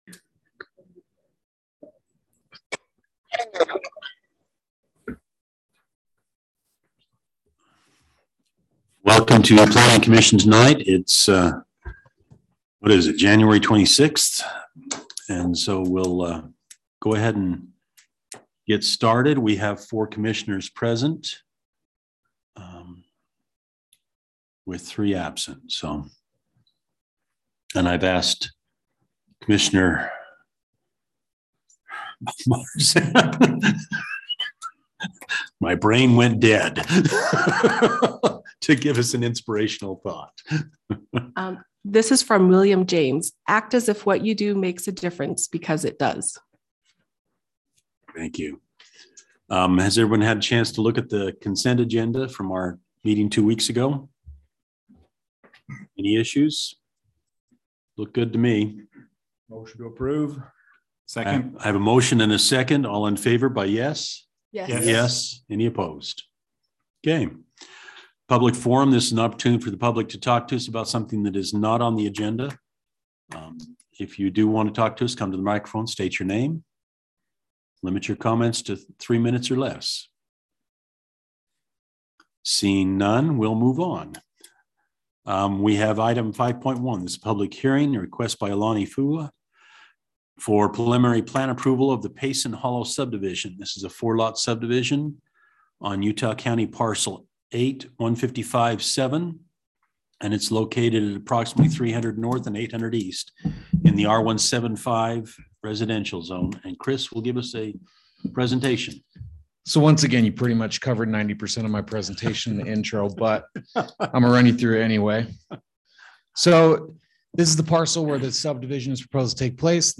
Meeting
Some commissioners may attend by electronic device.